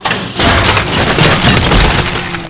recycle.wav